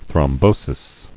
[throm·bo·sis]